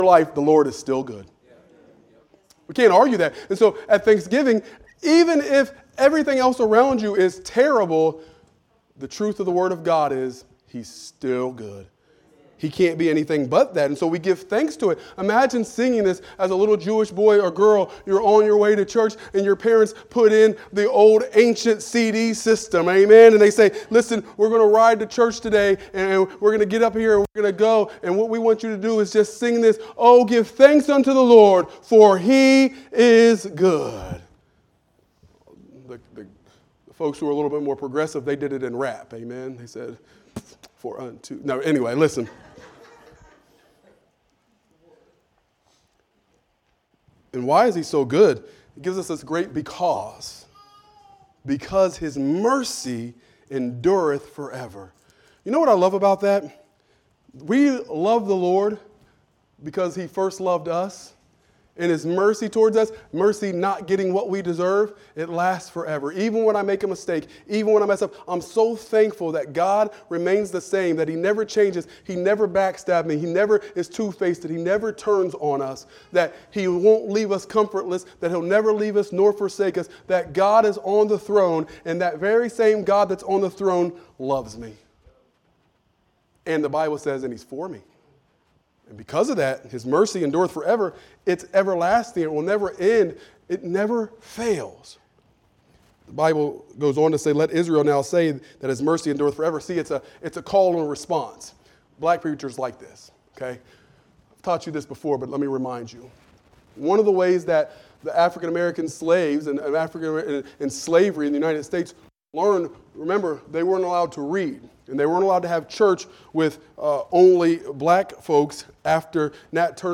Praisegiving Service